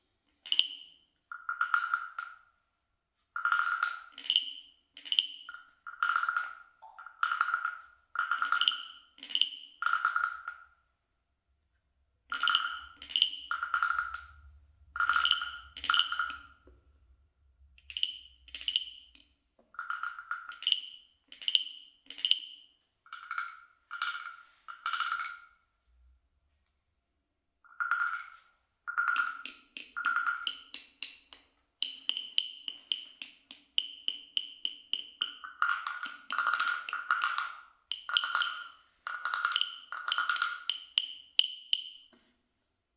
Chřestidla, štěrchátka, kvákadla
Chřestidla, štěrchátka, kvákadla a další "tvořiče" zvuků pocházejí z různých koutů světa, bývají vyráběny z přírodních materiálů a většinou dosti věrně napodobují všelijaké přírodní zvuky.
chrestidla-sterchatka-kvakadla196.mp3